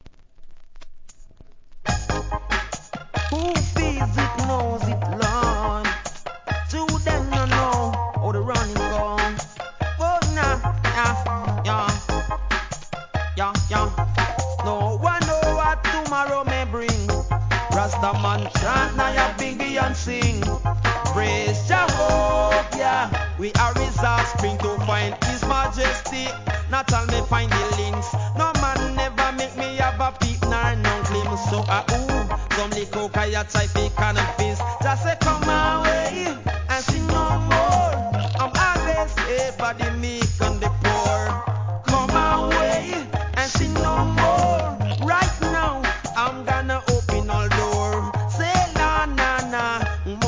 REGGAE
1995年のビンギ調RHYTHM!!